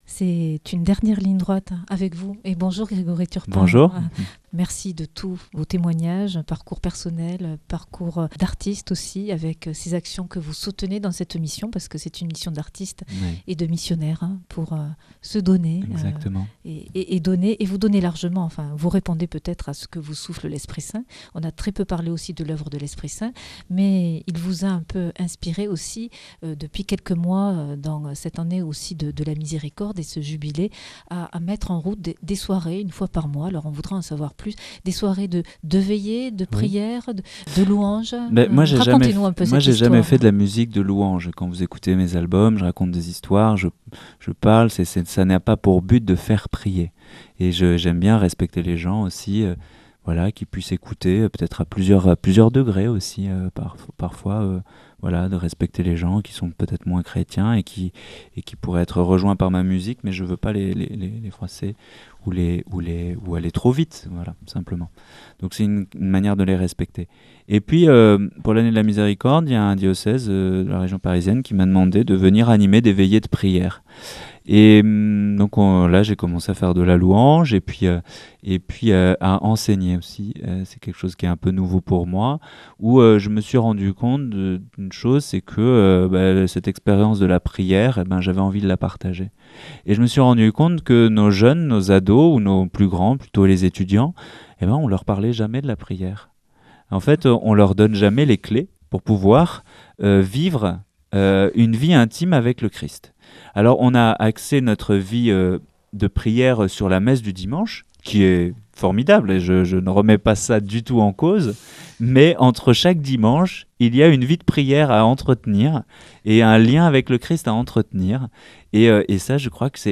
Chant -Louange - Prière- Enseignement